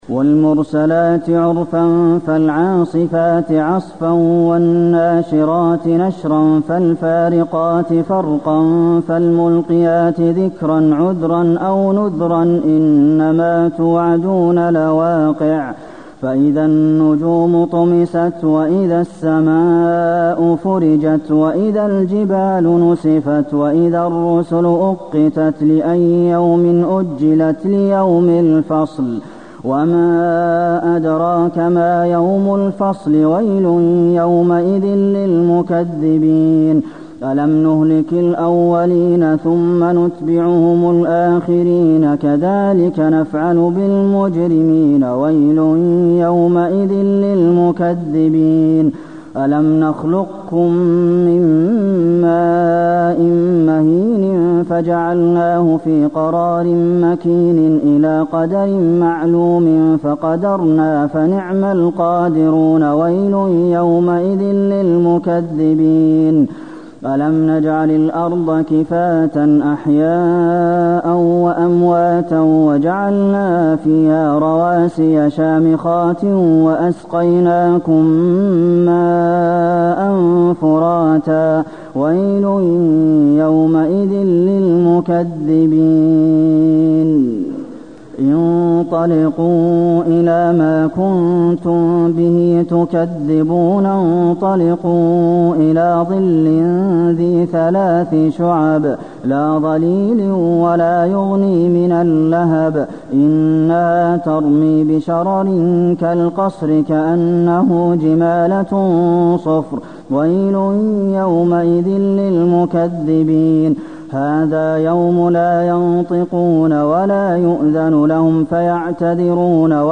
المكان: المسجد النبوي المرسلات